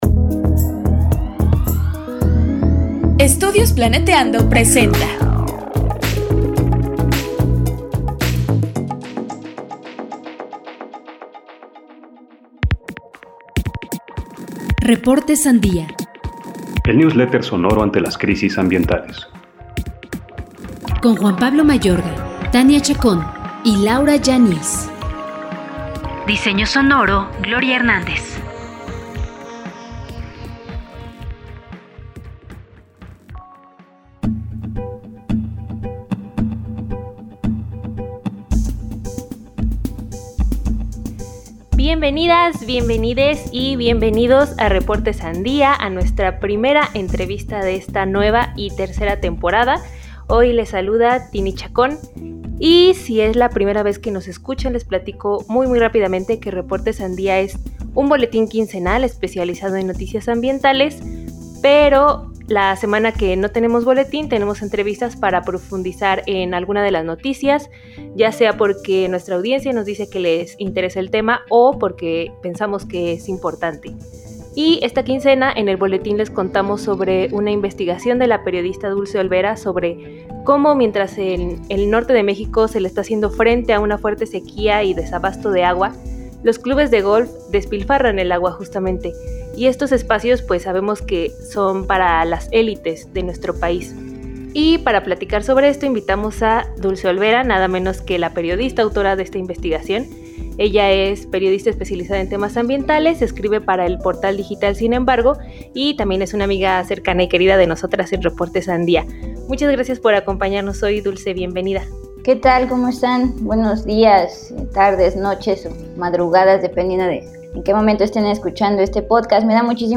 'La élite no conoce la sed'. Entrevista